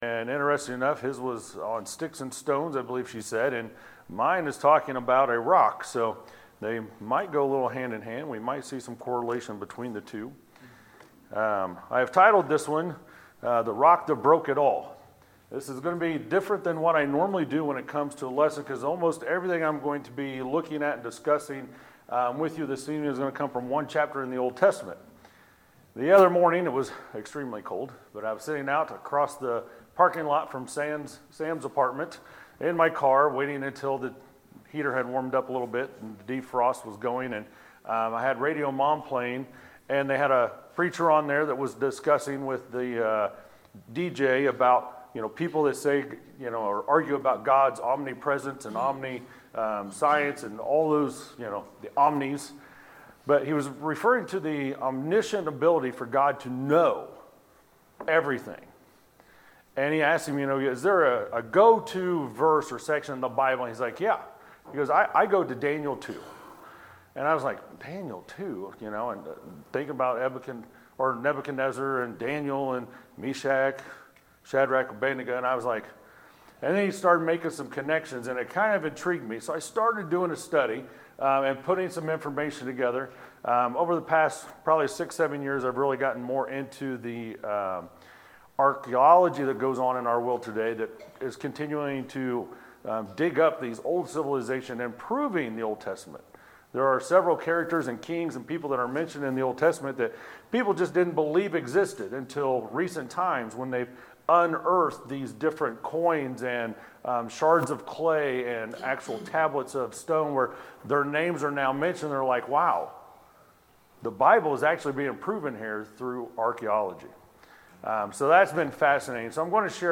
Sermons, November 24, 2019